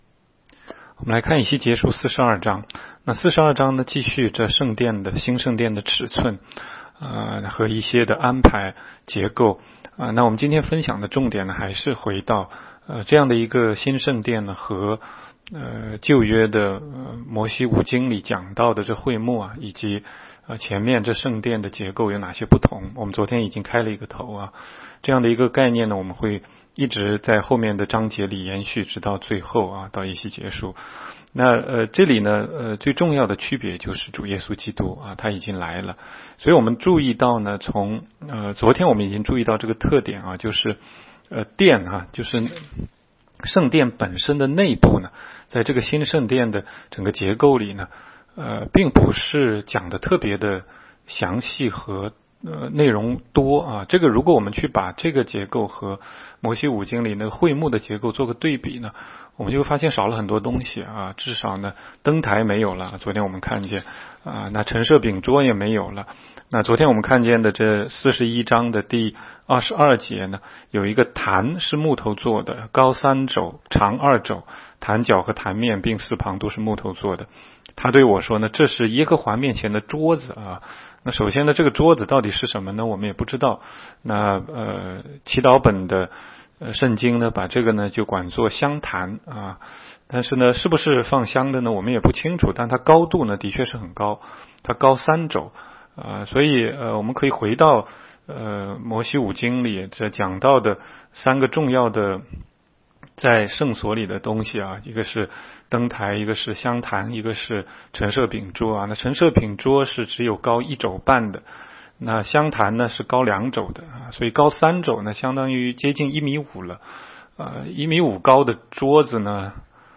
16街讲道录音 - 每日读经 -《以西结书》42章